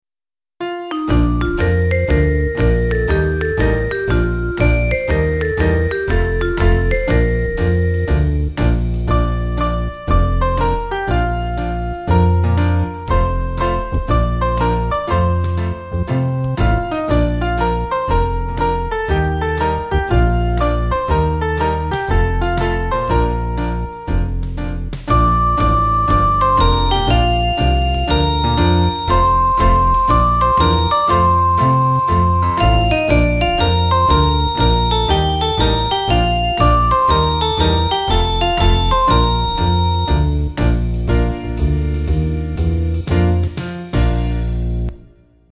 Kid`s club music
2/Bb